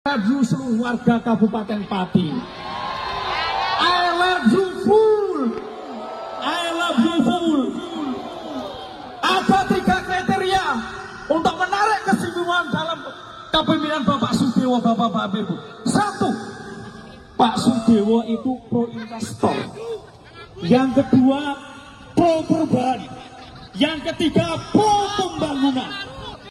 Ini adalah aksi cinta damai pendukung bupati Sudewo, yang menggelar acara istighosah di lapangan Kayen 28 - 8 - 25. Yang hadir lebih dominan emak, berbeda dengan Aliansi Masyarakat Pati bersatu yang pesertanya dominan dari kalangan pemuda.